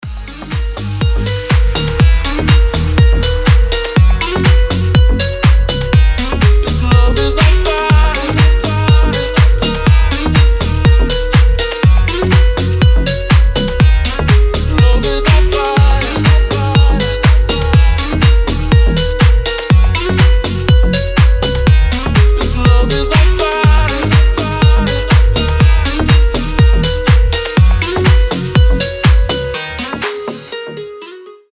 • Качество: 320, Stereo
мужской голос
deep house
мелодичные